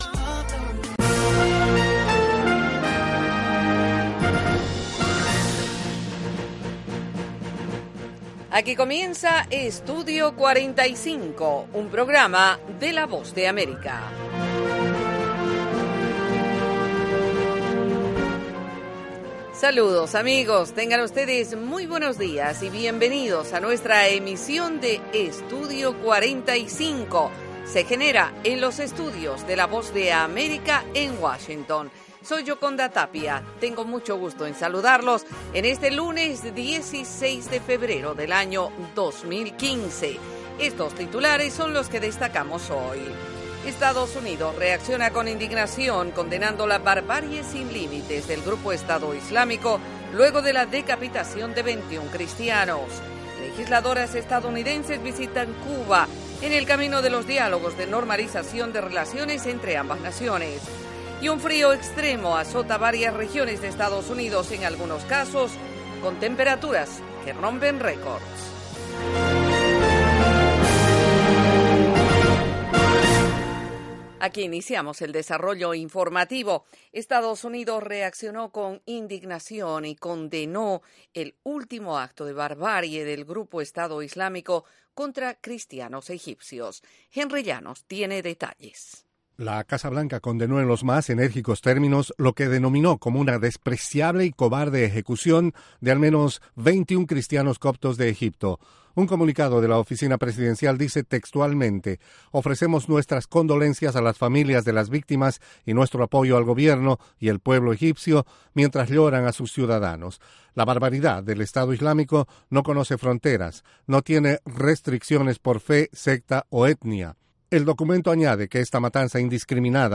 Treinta minutos de la actualidad noticiosa de Estados Unidos con análisis y entrevistas.